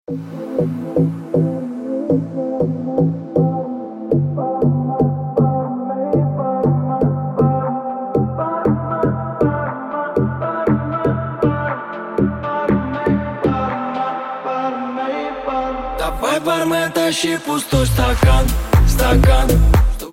• Качество: 128, Stereo
поп
нарастающие